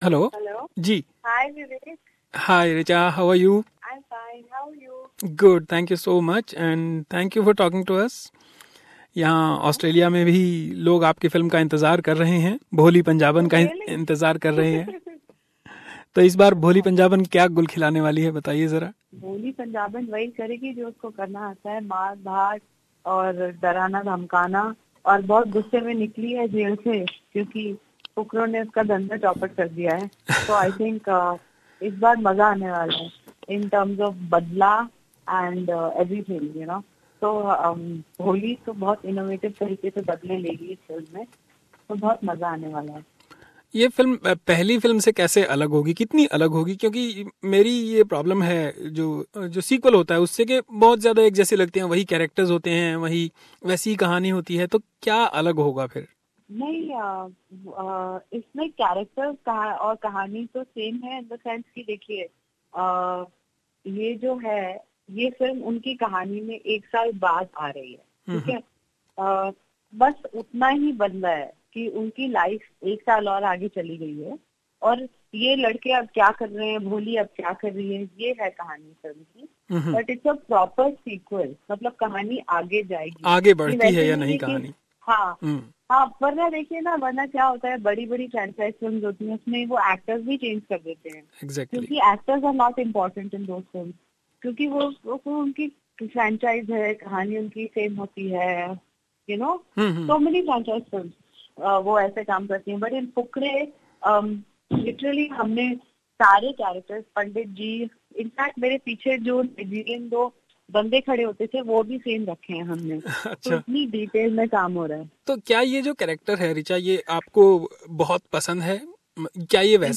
Take a listen to this complete interview.